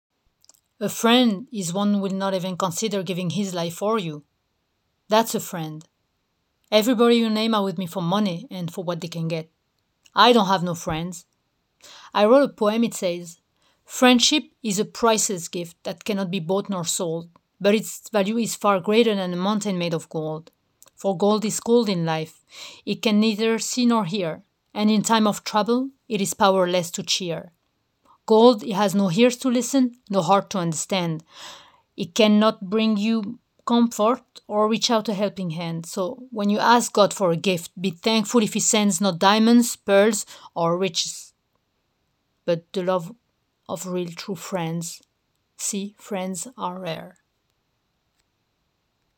Bandes-son
28 - 40 ans - Mezzo-soprano